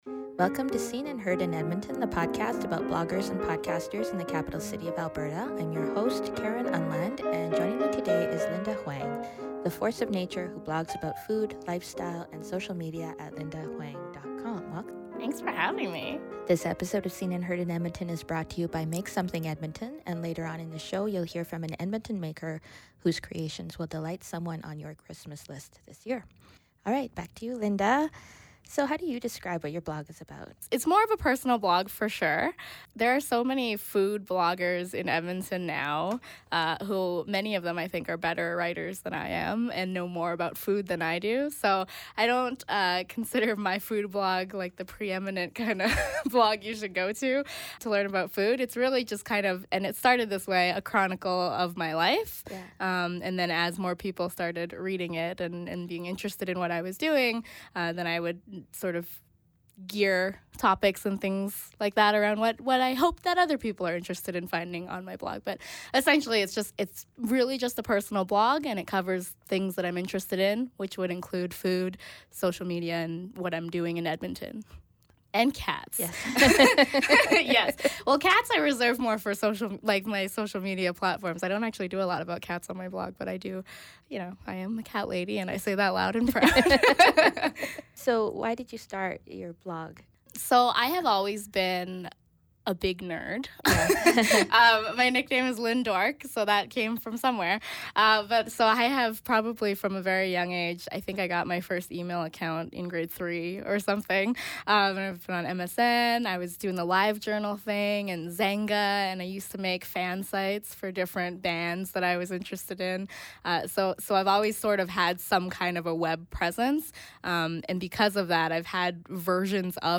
Oh my goodness, did we ever laugh a lot in this interview.